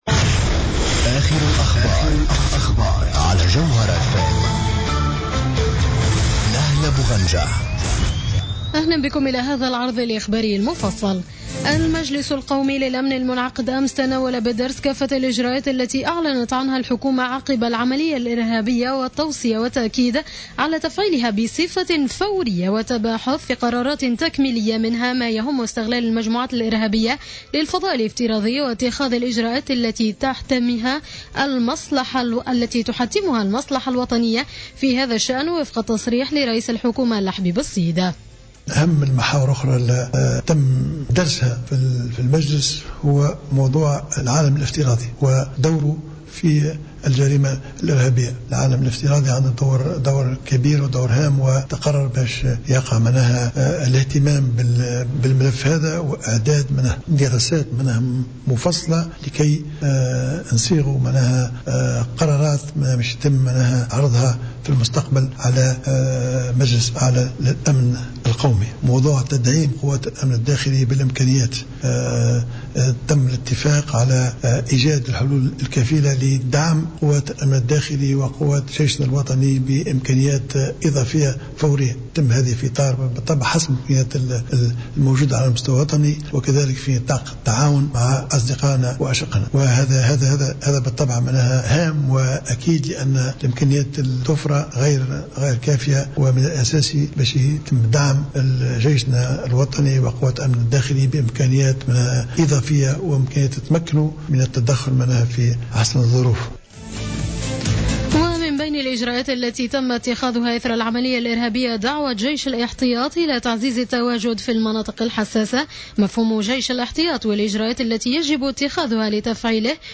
نشرة أخبار منتصف الليل ليوم الاثنين 29 جوان 2015